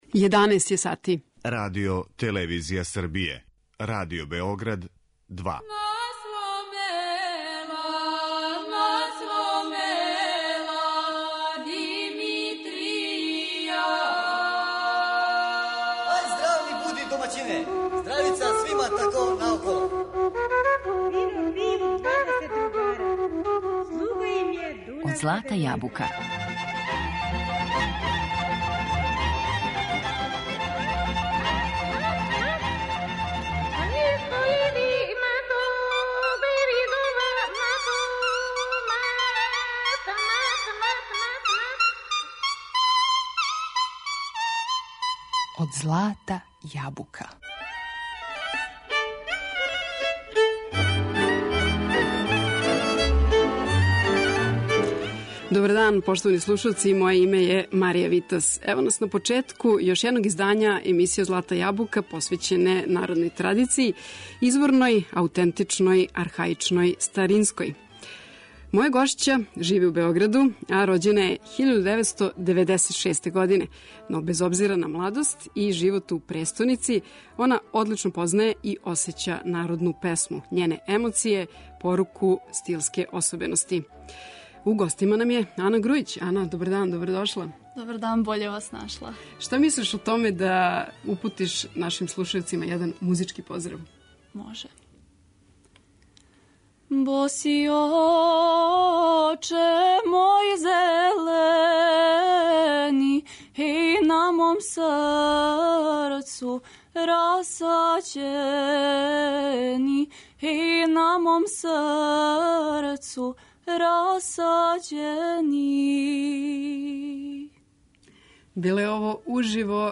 Снимци сведоче, између осталог, о изврсном познавању различитих традиционалних вокалних стилова, са простора централне, југоисточне, североисточне Србије,Бачке, Косова, Босне...